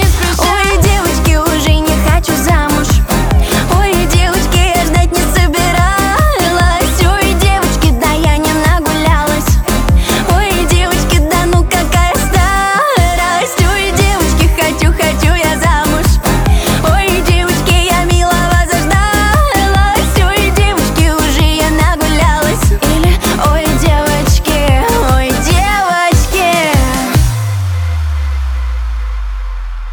Жанр: Русская поп-музыка / Поп / Русские